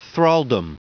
Prononciation du mot thraldom en anglais (fichier audio)
Prononciation du mot : thraldom